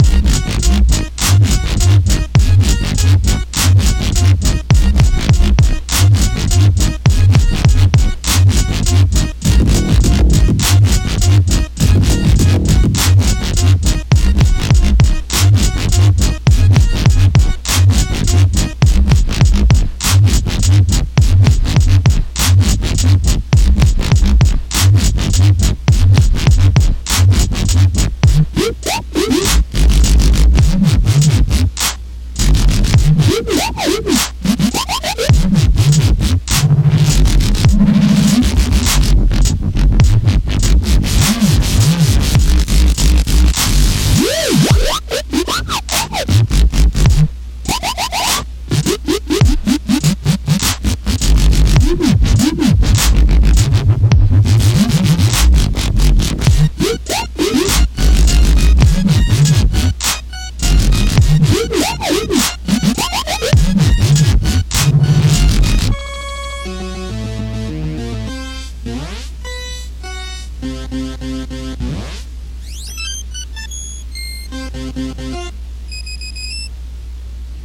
vaporwave, chiptune, videogame music, vgm, midiwave,